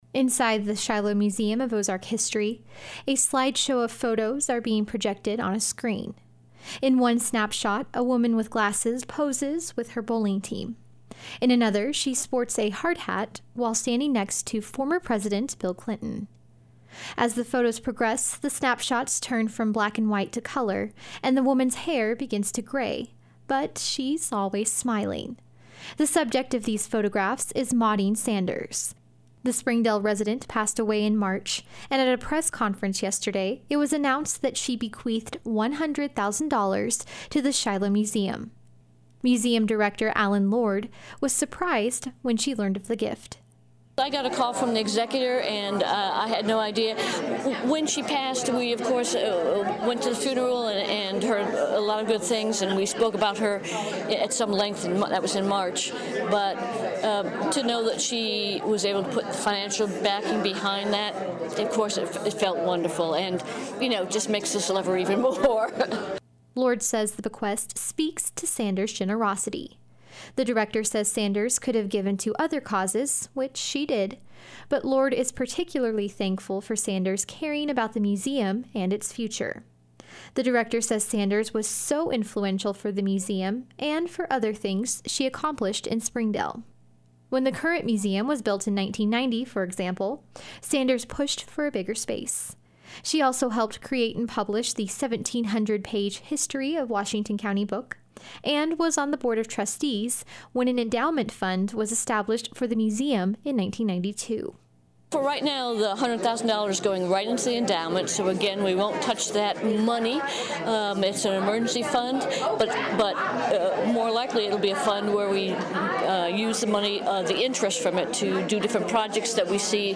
More from yesterday’s news conference at the Shiloh Museum of Ozark History can be heard here.